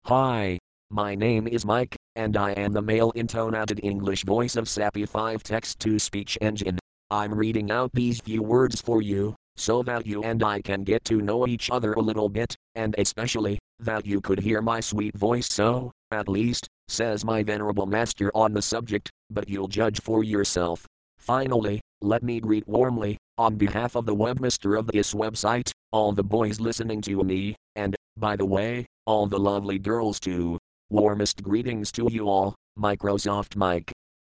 Texte de démonstration lu par Microsoft Mike (Voix masculine anglaise Sapi 5.1)
Écouter la démonstration de Microsoft Mike (Voix masculine anglaise Sapi 5.1)